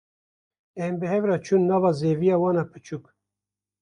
(IPA) olarak telaffuz edilir
/t͡ʃuːn/